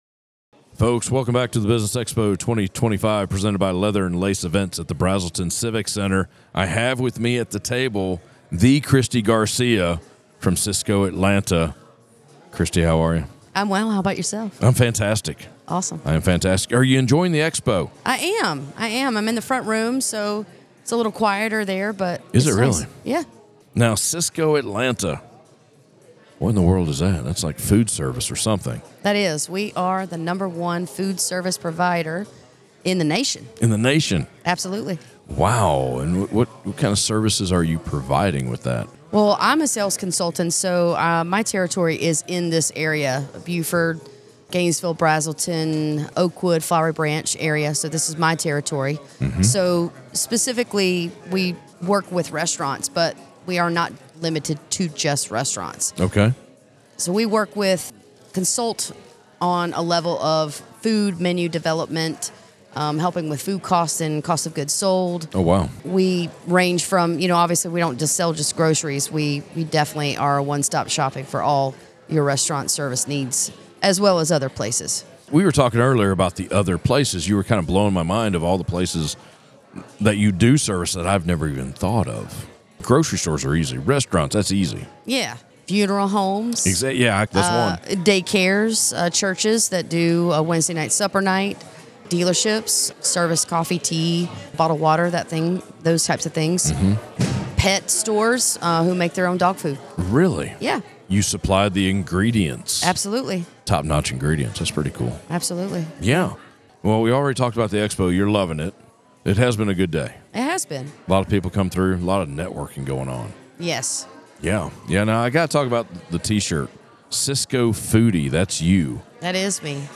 Northeast Georgia Business RadioX – the official Podcast Studio of the Business Expo 2025